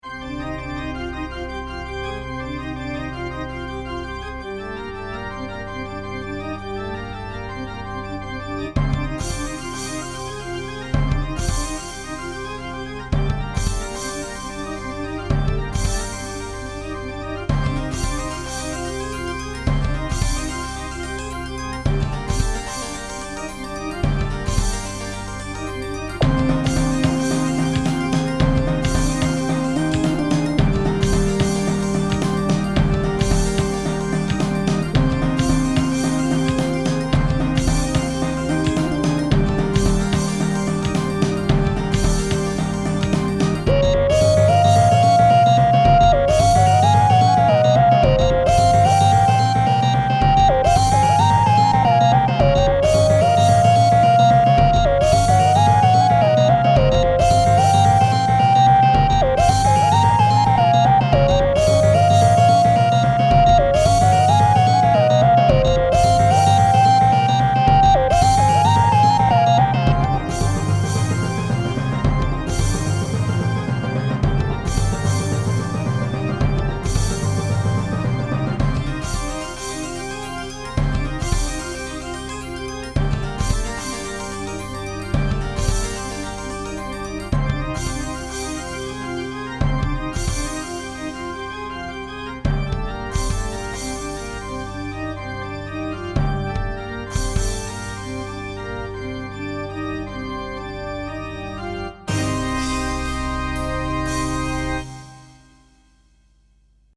Pieza de rock sinfónico
música rock sinfónico